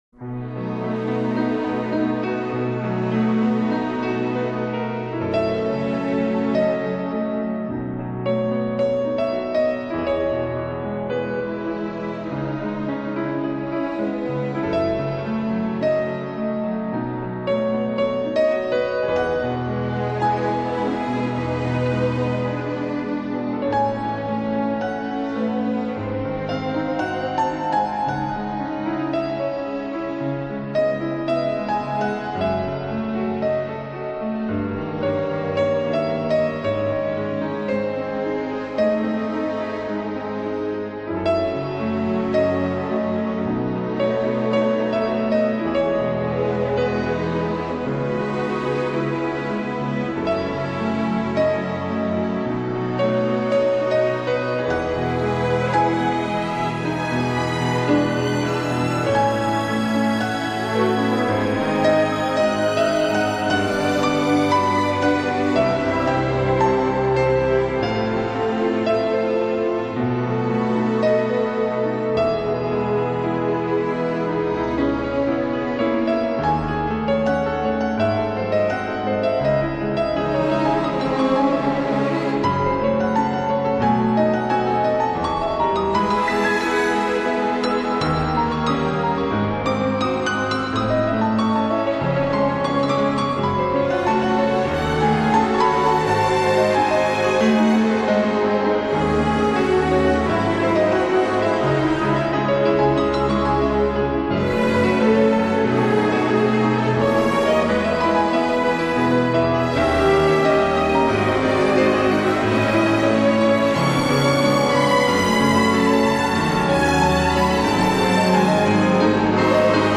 经过重新编曲和演绎,更加细腻感人,堪称展现其浪漫音乐的经典之作。